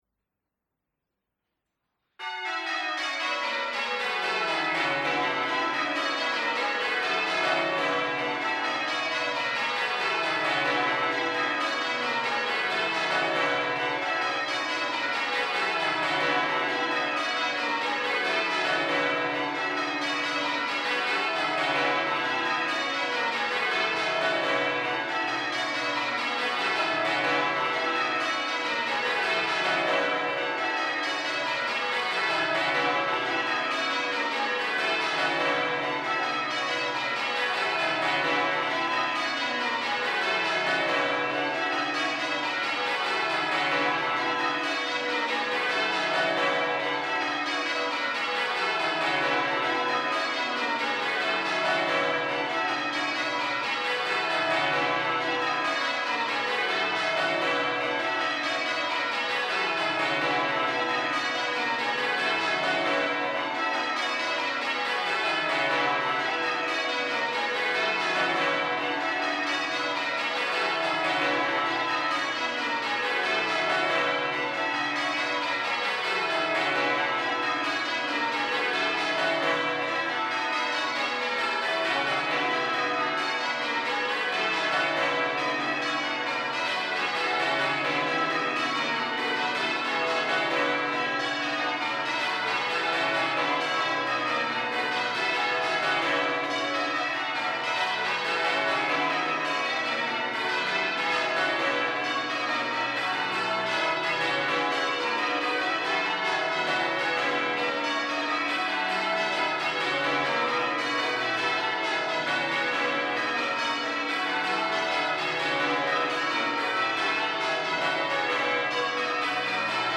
In all there were 27 competing bands this year across the qualifiers and final, with most of the finalist bands experienced on heavy rings of bells.